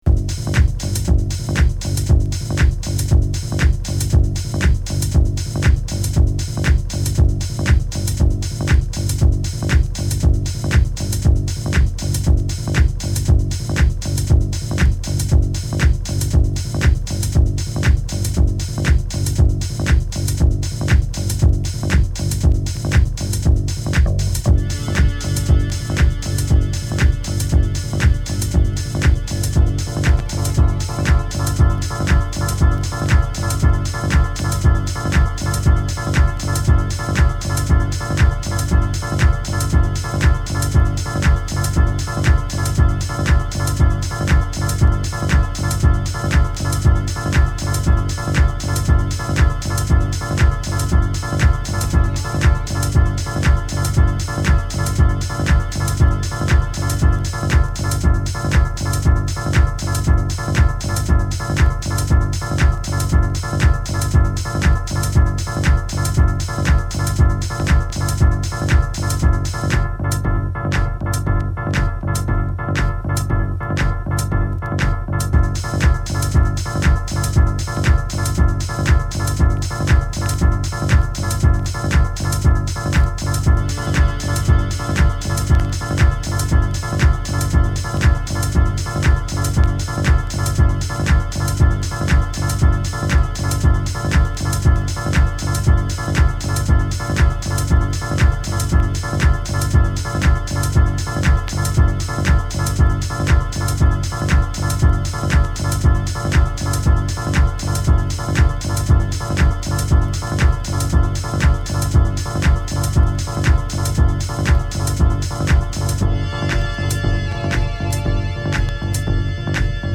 マシーナリーなビートダウン要素も垣間見れるトラックになっています。
House / Techno